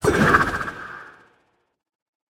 Minecraft Version Minecraft Version 1.21.4 Latest Release | Latest Snapshot 1.21.4 / assets / minecraft / sounds / item / trident / riptide2.ogg Compare With Compare With Latest Release | Latest Snapshot
riptide2.ogg